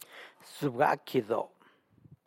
San Lucas Quiaviní Zapotec Talking Dictionary